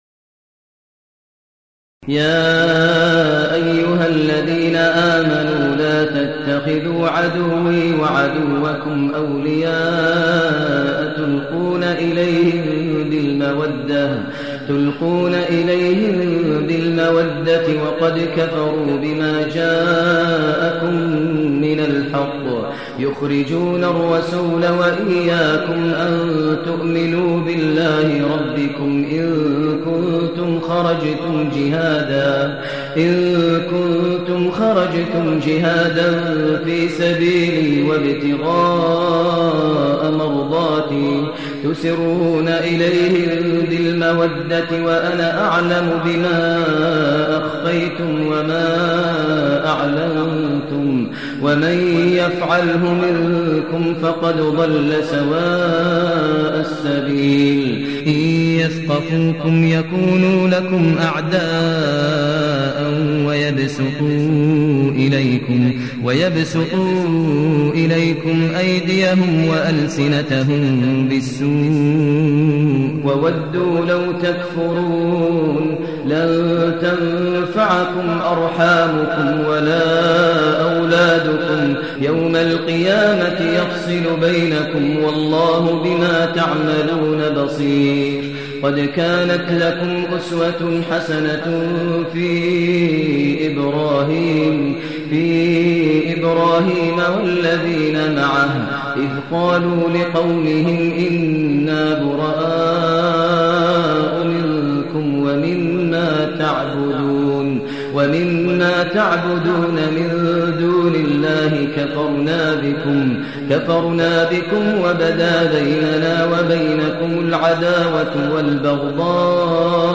المكان: المسجد النبوي الشيخ: فضيلة الشيخ ماهر المعيقلي فضيلة الشيخ ماهر المعيقلي الممتحنة